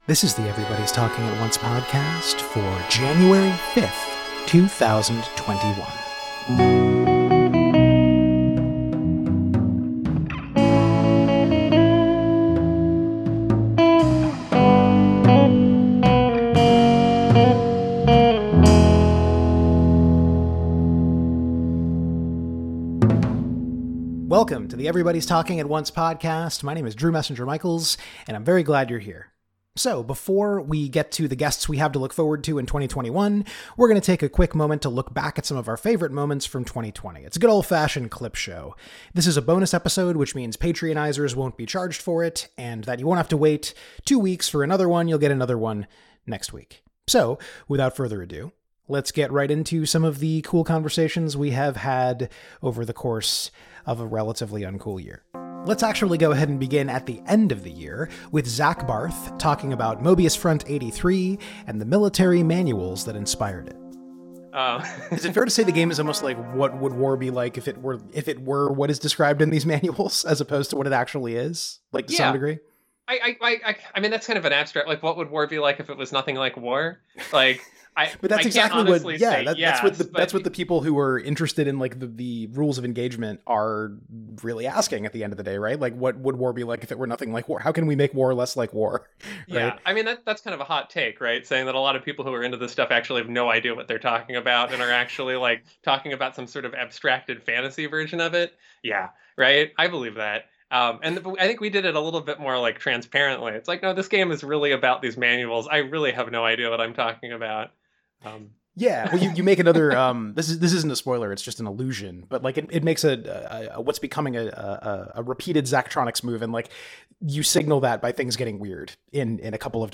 For our hundredth episode, we're trying something a little different and editing together some of our favorite moments from the past year of the 'cast.